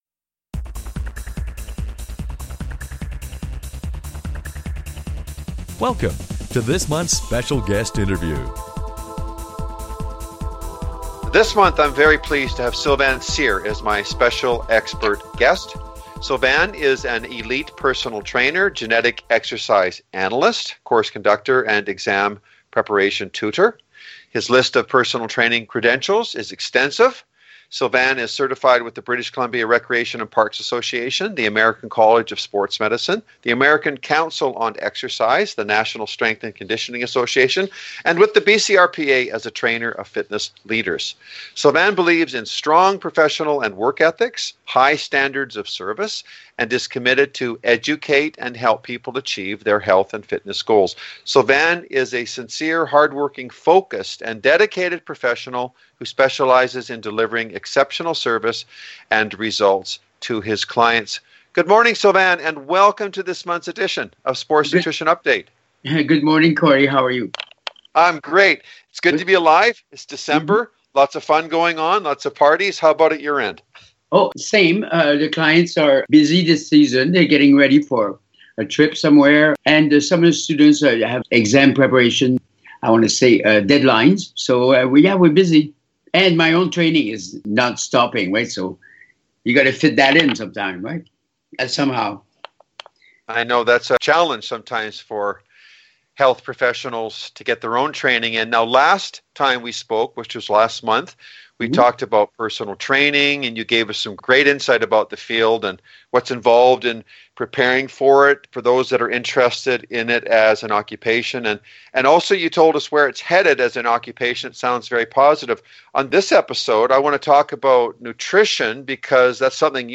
Special Guest Interview Volume 16 Number 12 V16N12c